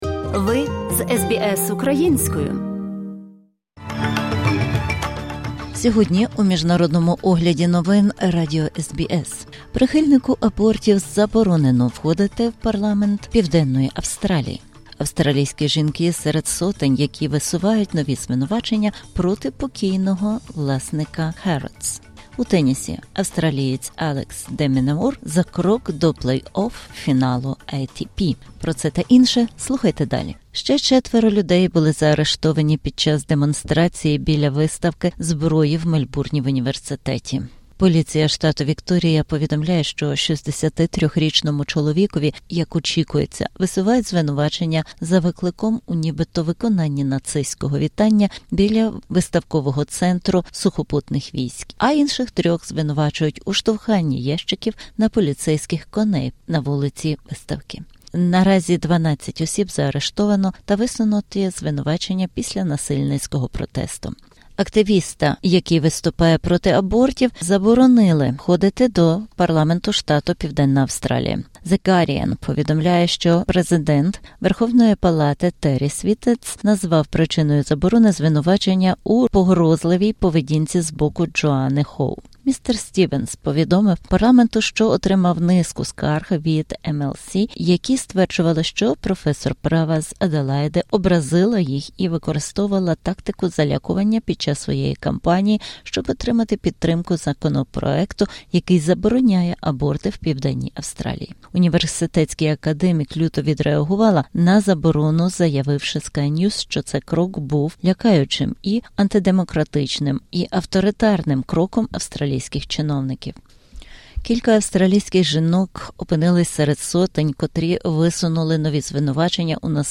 Огляд SBS новин українською мовою.